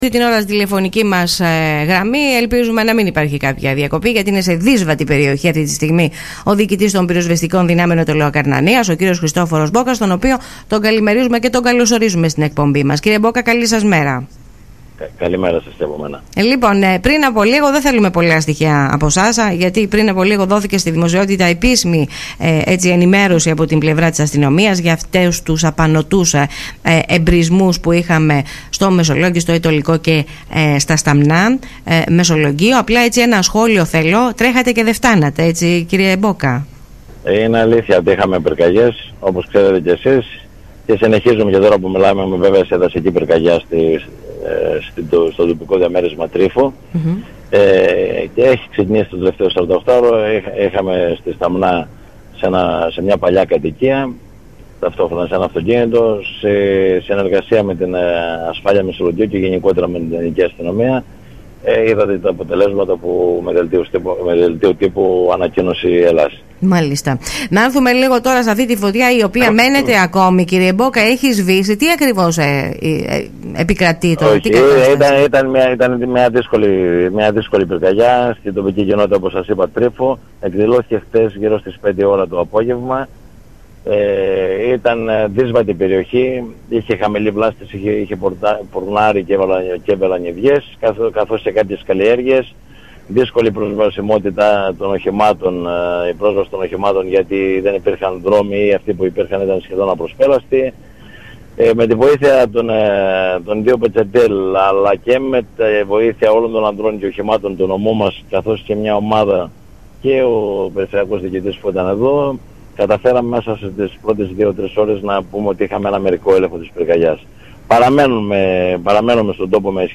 Eπίσης απάντησε σε ερωτήματα της δημοσιογράφου για τους εμπρησμούς στο Μεσολόγγι αλλά και τις τελυταίες μεταθέσεις πυροσβεστών από την Αιτωλοακαρνανία σε άλλες περιοχές, που έχουν φέρει αντιδράσεις. Ακούστε την συνέντευξη https